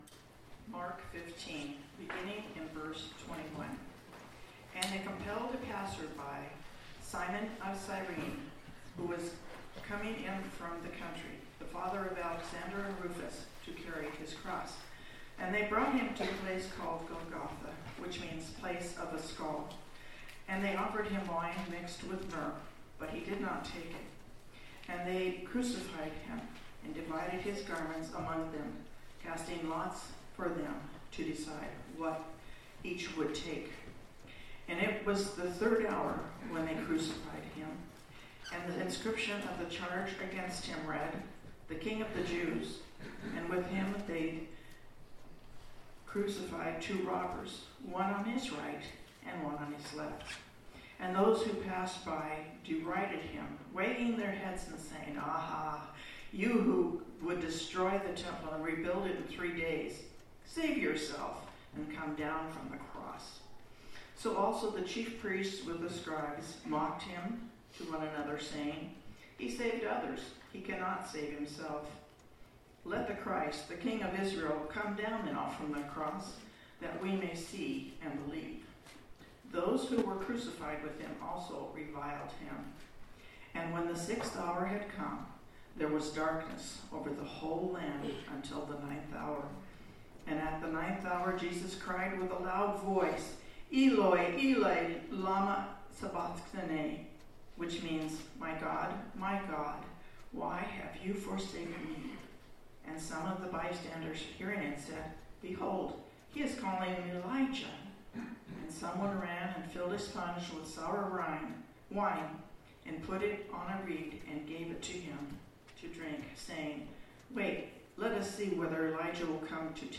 Last Seven Sayings of Jesus Passage: Matthew 27:32-54 Service Type: Sunday Morning Topics